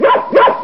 dog_bark.mp3